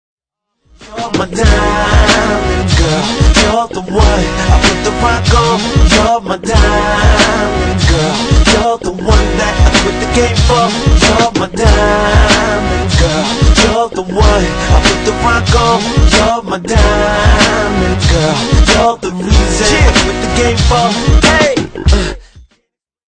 tamil ringtonedance ringtonemobile ringtoneparty ringtone